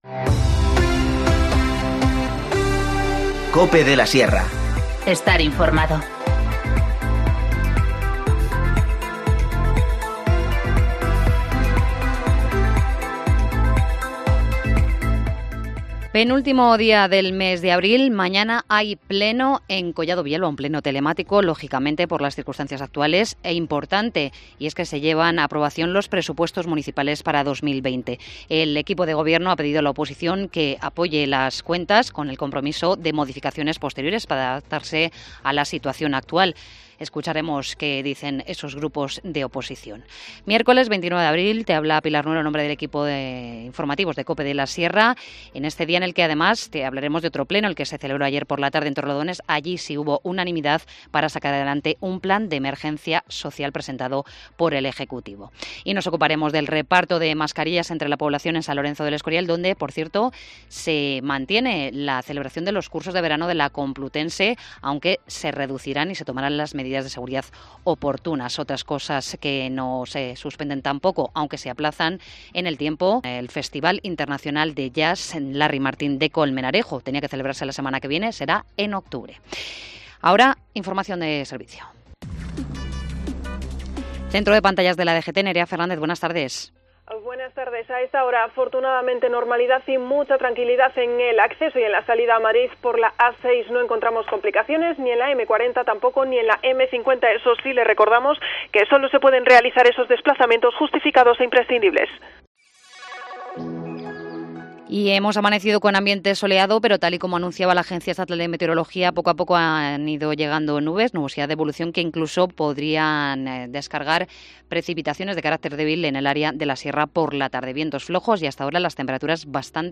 Informativo Mediodía 29 abril 14:20h
INFORMACIÓN LOCAL